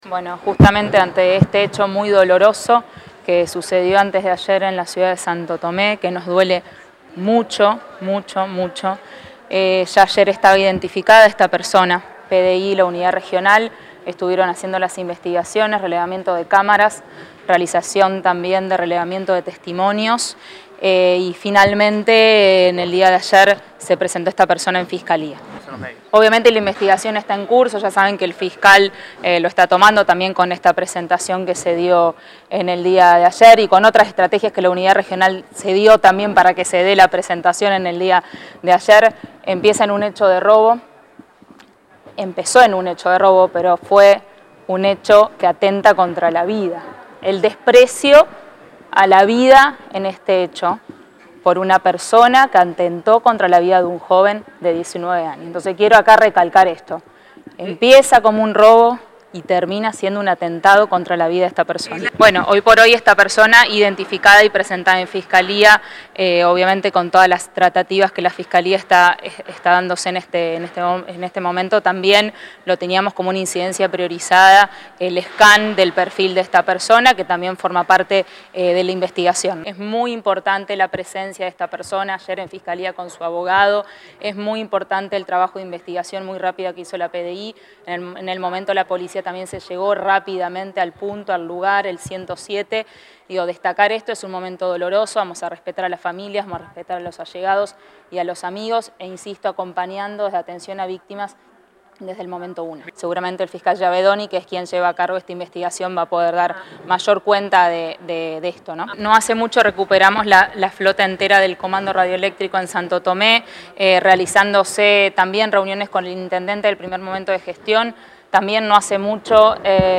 En el hall del Ministerio de Seguridad, la funcionaria explicó que en el marco de la investigación por “este hecho muy doloroso, que sucedió antes de ayer en la ciudad de Santo Tomé, ya ayer estaba identificada esta persona. La PDI (Policía de Investigaciones) y la Unidad Regional I de la Policía estuvieron haciendo las pesquisas, relevamiento de cámaras, de testimonios y finalmente ayer se presentó esta persona en la Fiscalía”.
Secretaria de Gestión Institucional del Ministerio de Justicia y Seguridad, Virginia Coudannes.